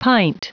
Prononciation du mot pint en anglais (fichier audio)
Prononciation du mot : pint